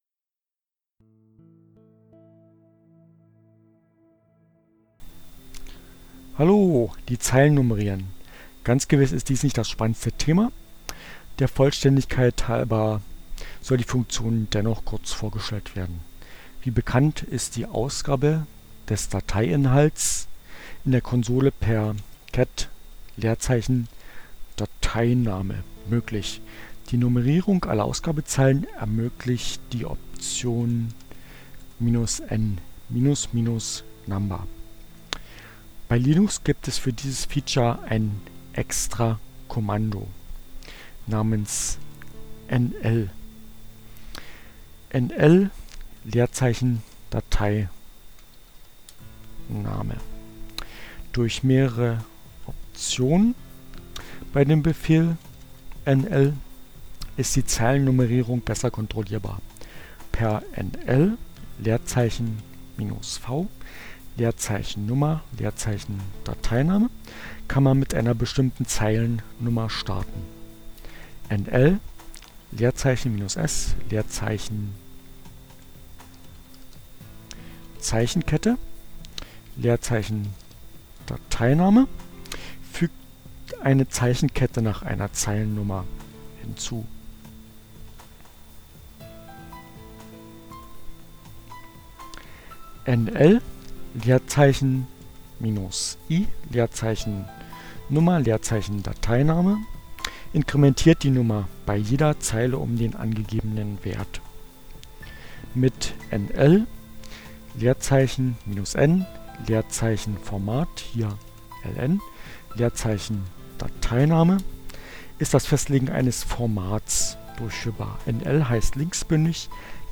Tags: cat, CC by-sa, gedit, Linux, Neueinsteiger, Ogg Theora, ohne Musik, screencast, Textverarbeitung, ubuntu, LibreOffice, nl